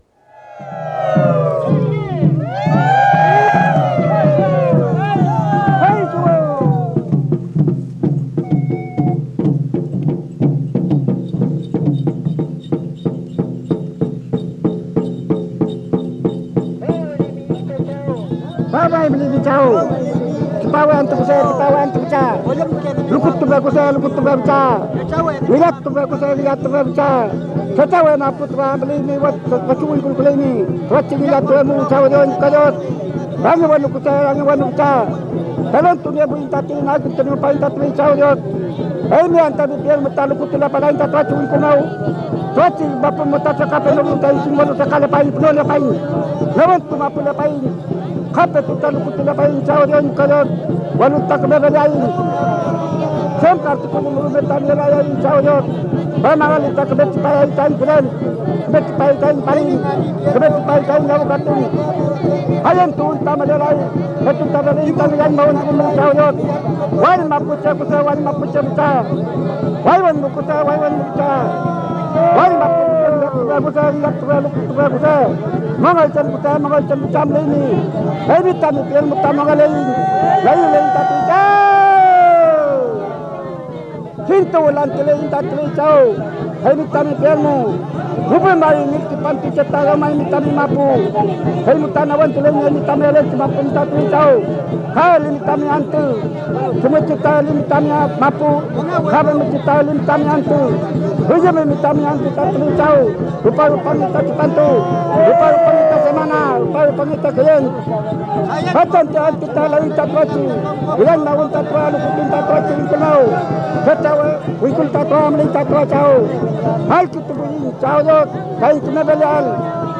Música mapuche (Comunidad de Collinque, Lumaco)
Música tradicional